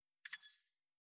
358七味を振る,一味,唐辛子,調味料,カシャカシャ,サラサラ,パラパラ,ジャラジャラ,サッサッ,ザッザッ,シャッシャッ,シュッ,パッ,サッ,
効果音厨房/台所/レストラン/kitchen